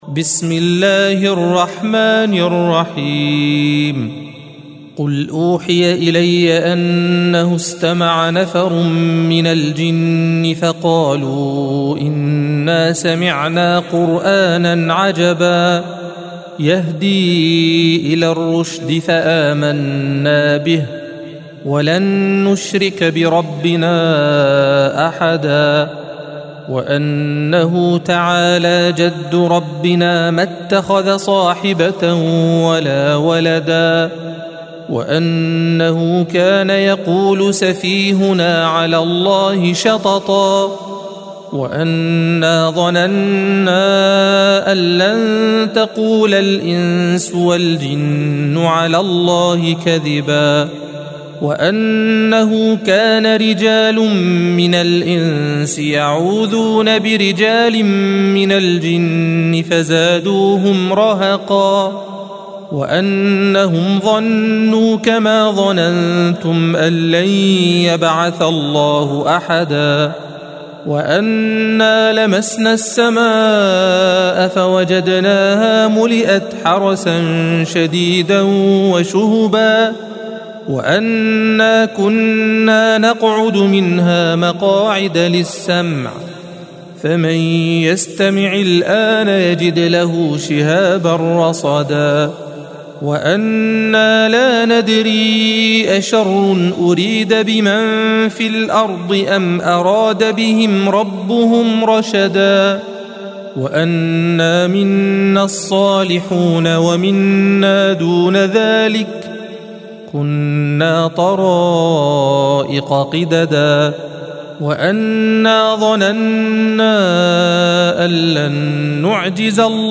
الصفحة 572 - القارئ